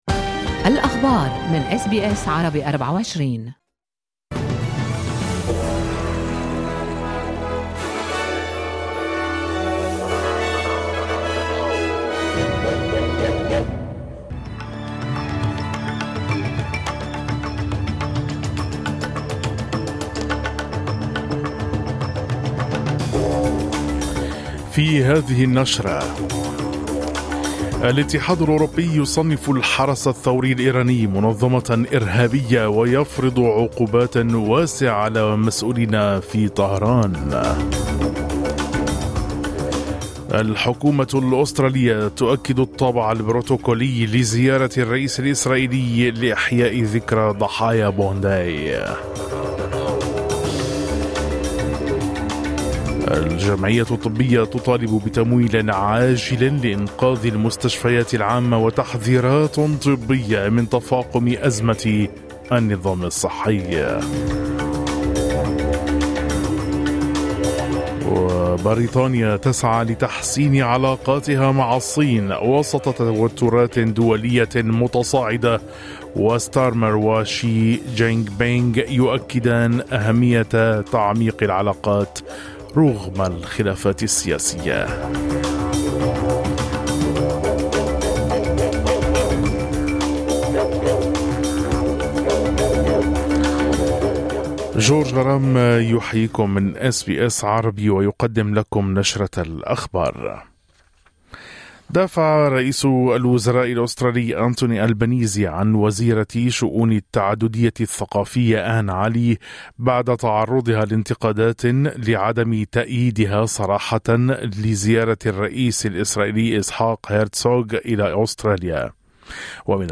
نشرة أخبار الصباح 30/01/2026
نشرة الأخبار